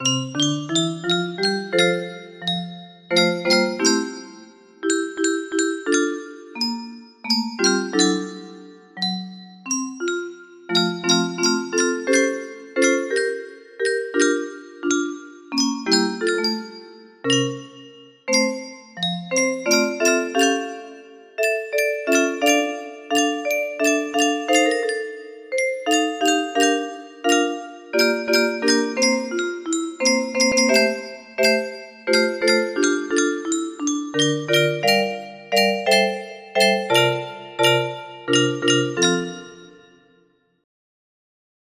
Refrein 60 tonen music box melody
Full range 60